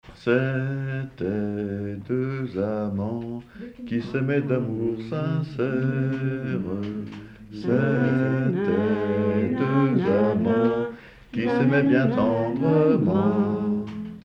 enfantine : berceuse
Pièce musicale inédite